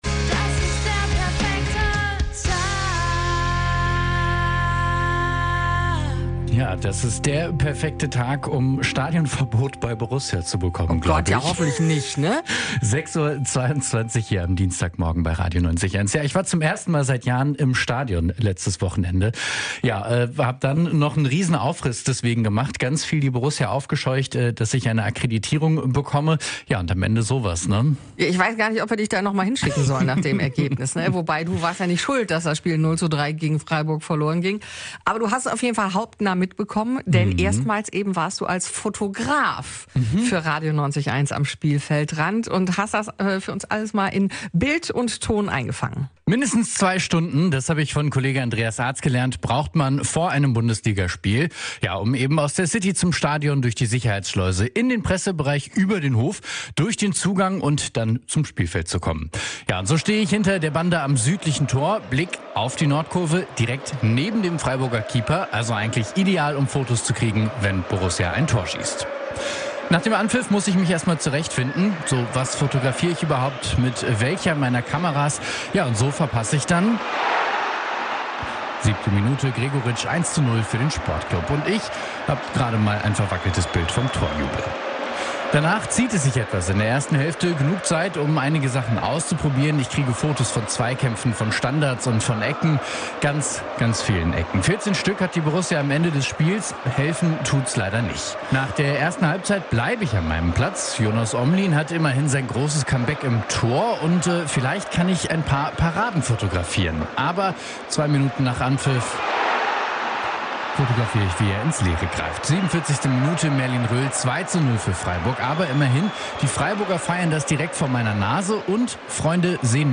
RADIO 90,1 | Beitrag zum Nachhören